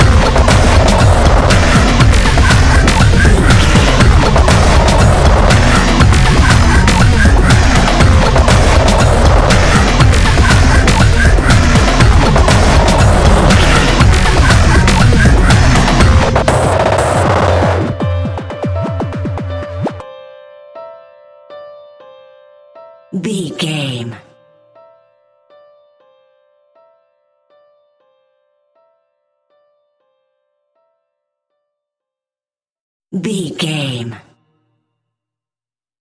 Epic / Action
Fast paced
Atonal
intense
energetic
aggressive
dark
piano
synthesiser
drum machine
breakbeat
synth bass